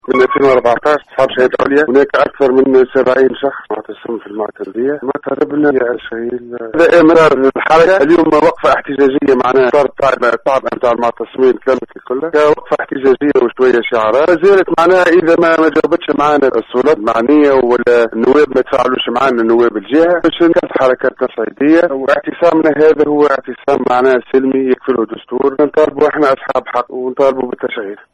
نفذ عدد من المعطلين عن العمل من حاملي الشهائد العليا بمعتمدية سبيطلة اليوم الاربعاء 6 افريل, وقفة احتجاجية امام مقر  المعتمدية للمطالبة بتشغيلهم وخاصة ان فترة بطالتهم قد طالت وسط تدهور ظروفهم الإجتماعية.